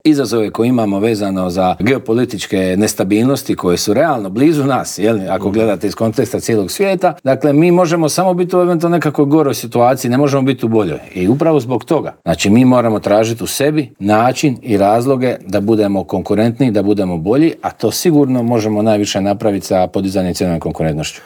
Inače, u prvih osam mjeseci došlo nam je 17,1 milijuna turista što je 2 posto više nego 2024. godine dok smo zabilježili i porast noćenja od 1 posto te ostvarili 89,9 milijuna noćenja o čemu smo u Intervjuu tjedna Media servisa razgovarali s ministrom turizma i sporta Tončijem Glavinom.